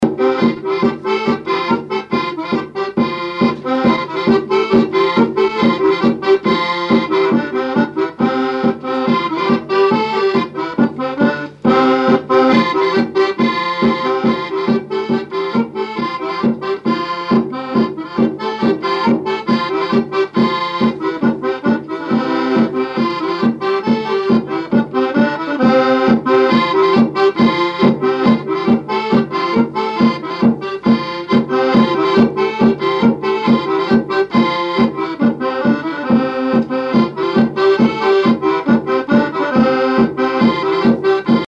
Divertissements d'adultes - Couplets à danser
branle : courante, maraîchine
répertoire à l'accordéon chromatique et grosse caisse
Pièce musicale inédite